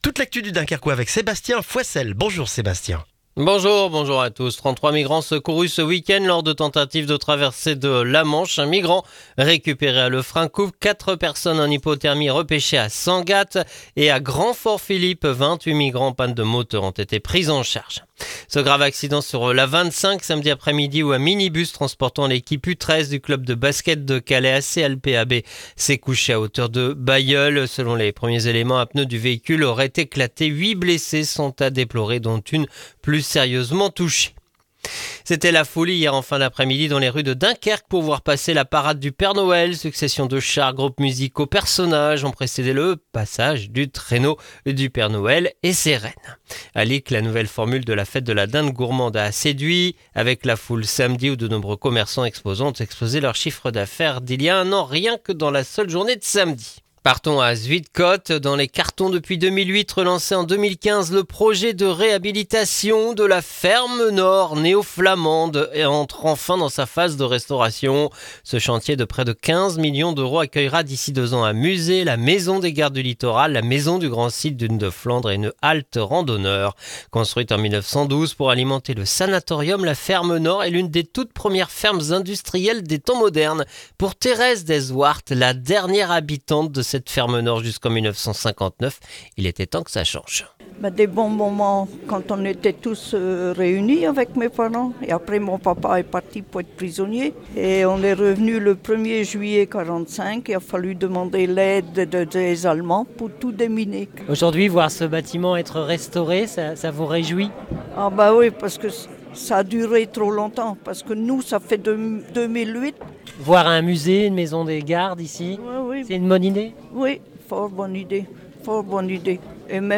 Le journal du lundi 15 décembre dans le dunkerquois